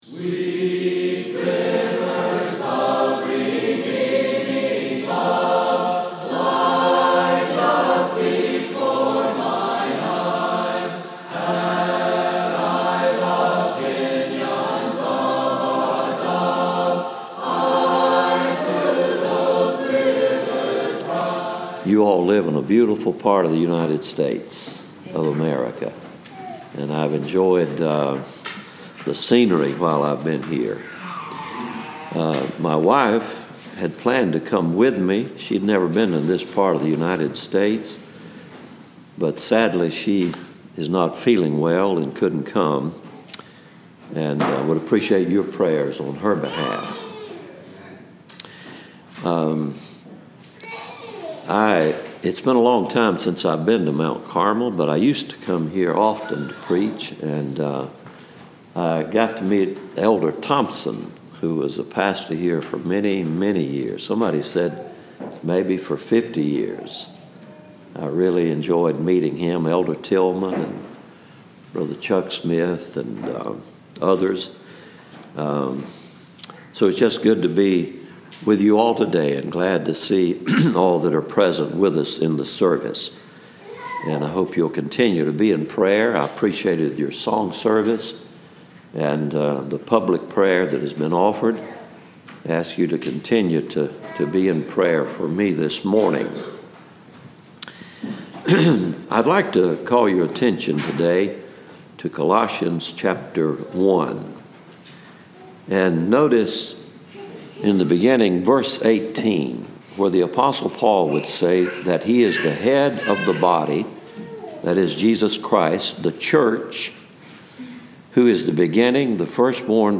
Sermoncast – His Preeminence
Mt Carmel Primitive Baptist Church